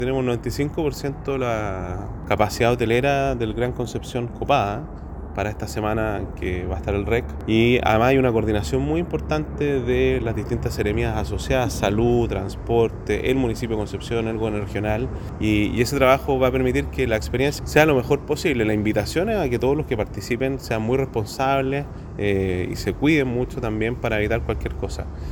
De hecho, el gobernador regional, Sergio Giacaman señaló que para el próximo fin de semana, el 95% de la capacidad hotelera del Gran Concepción ya está copada.